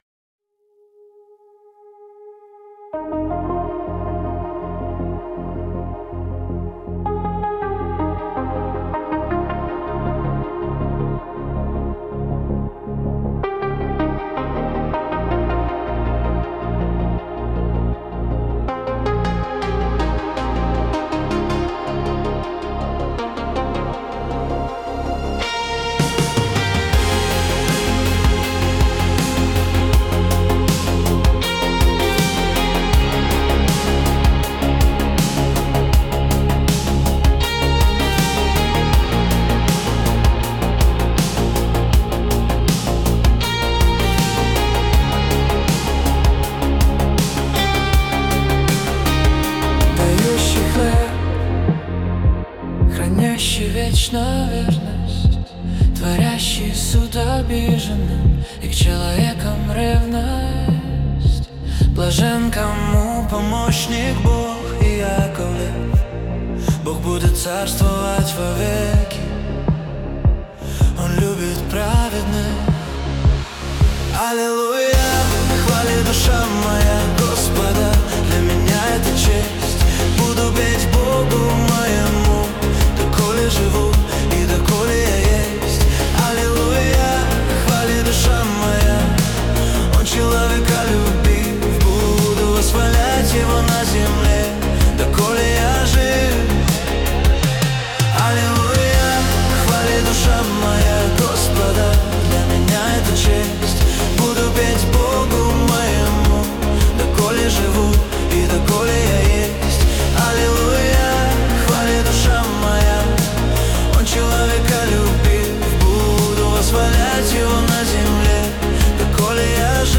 песня ai
25 просмотров 82 прослушивания 12 скачиваний BPM: 81